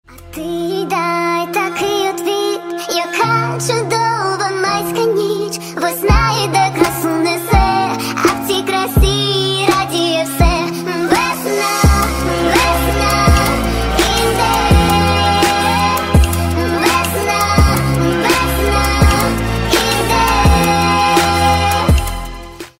• Качество: 128, Stereo
remix
романтические